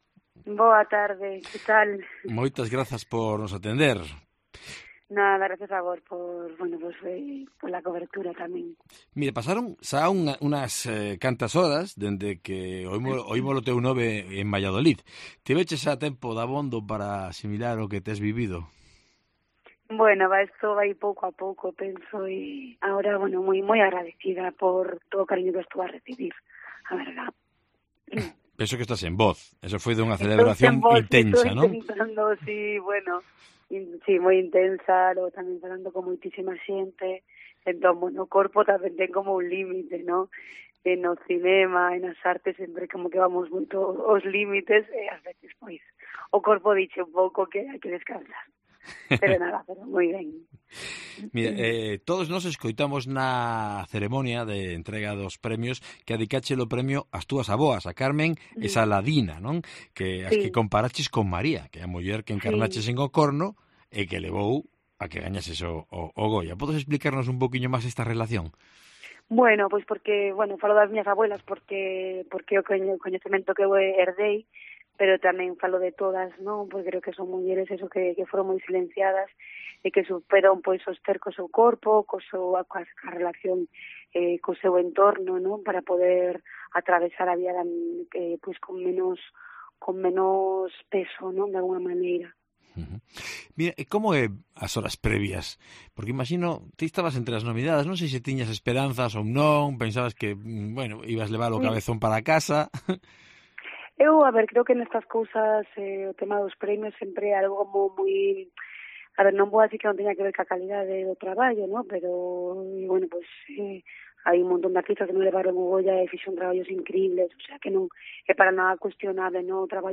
Entrevista con Janet Novás, Goya a la actriz revelación